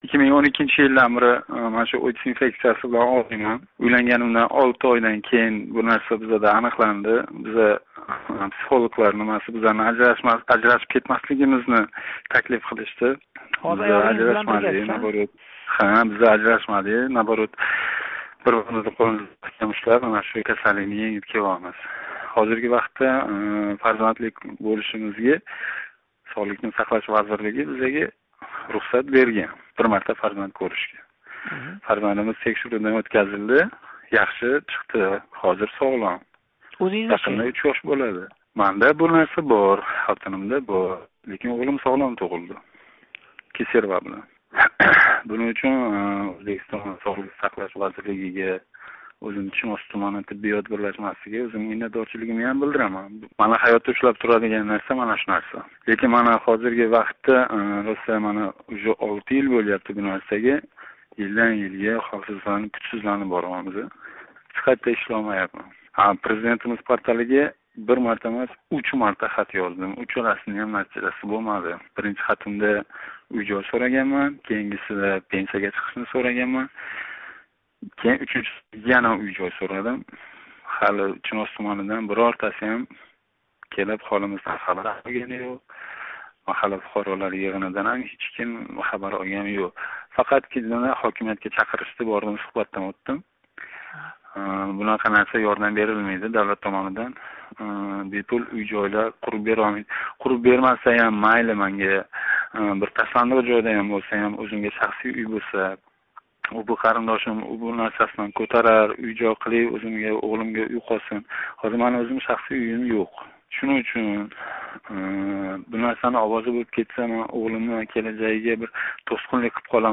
Чинозлик ОИТСга чалинган йигит билан суҳбат